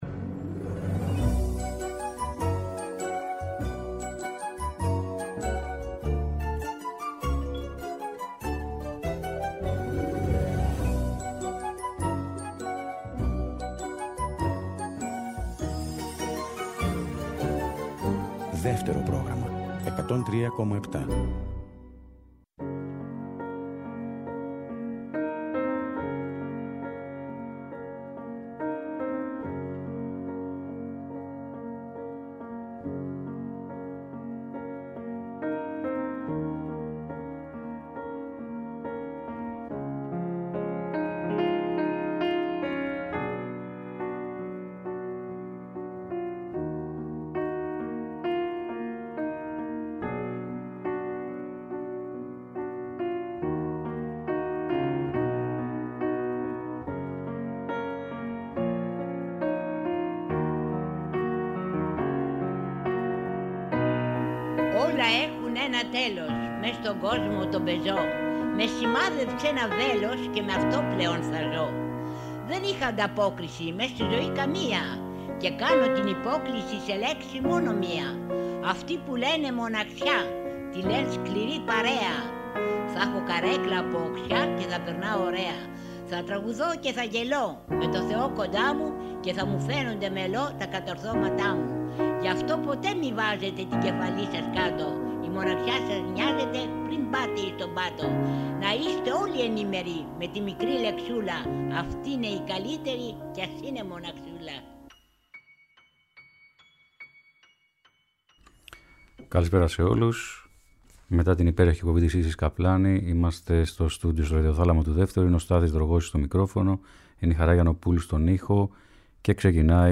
Τραγούδια πρωτότυπα, πηγαία, σουρεαλιστικά, αθώα, πολύπλοκα, παιδικά, σπαρακτικά.